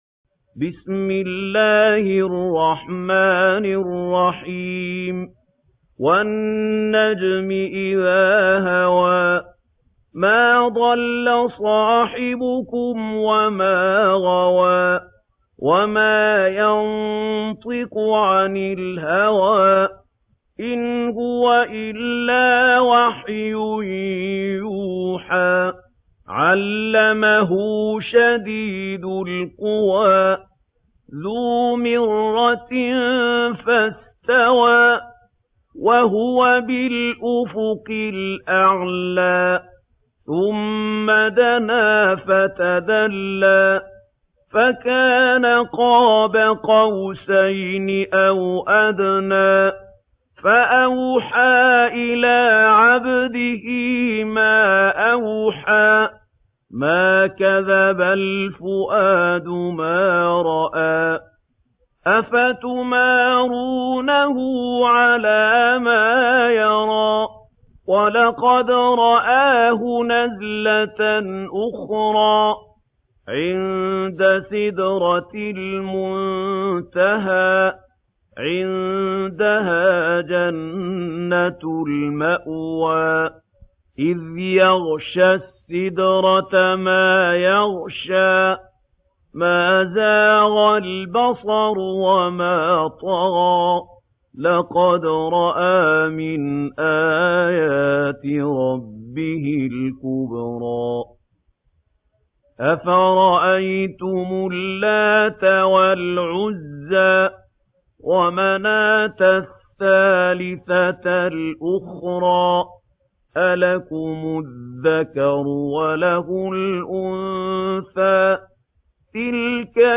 محمود خليل الحصري - بالقصر